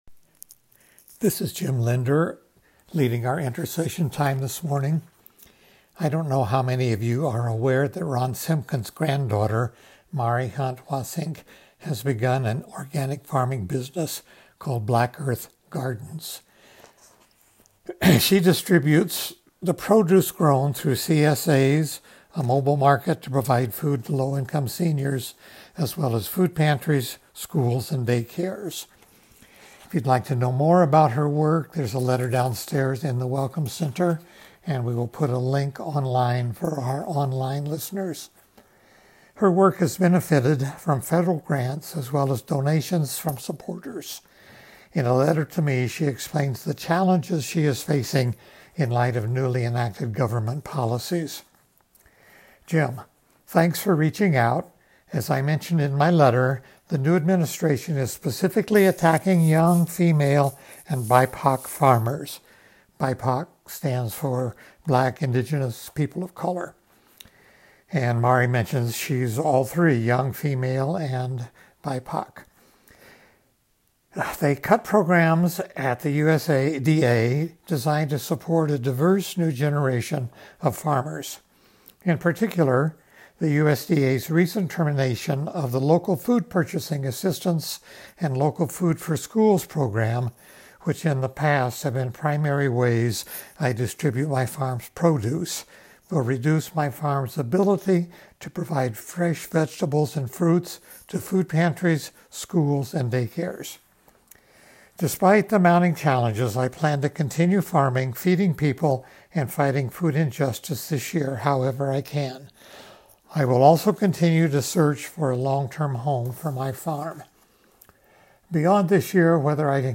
Prayer